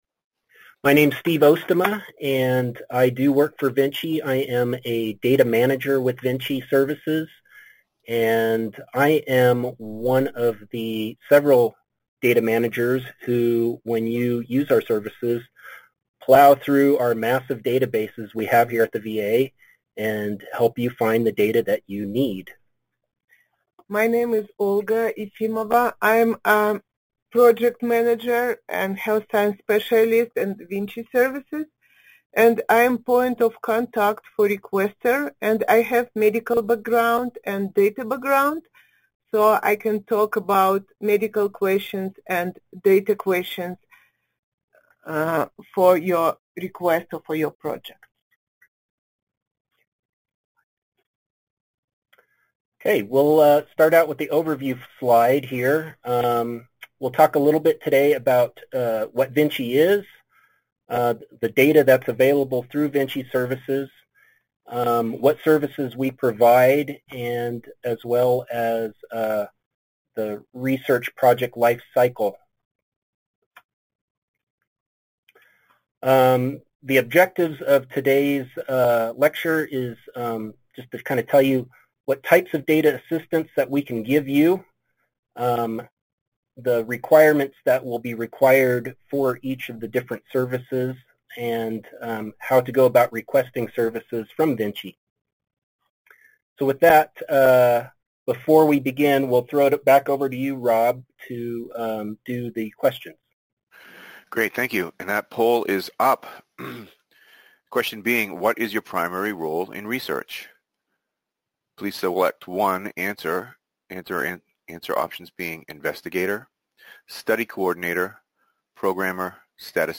Intended Audience: This Cyberseminar will be designed to provide advanced training, information and support for VINCI users as well as the larger HSR&D research community.